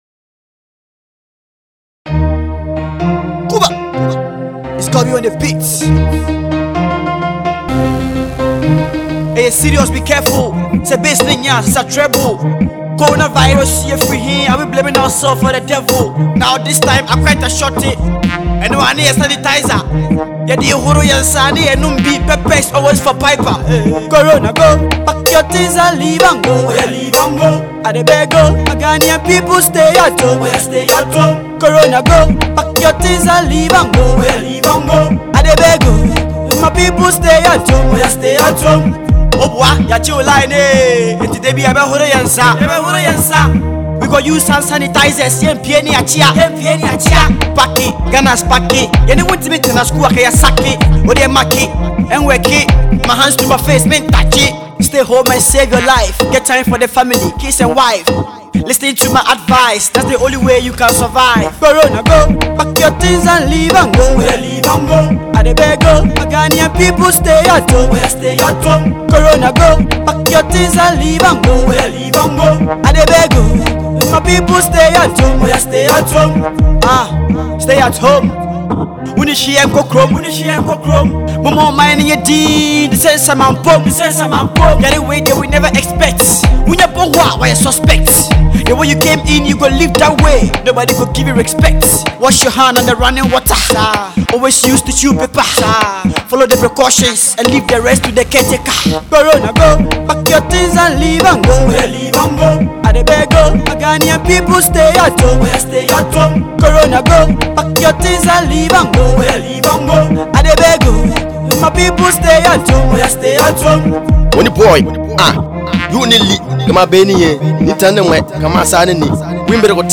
catchy freestyle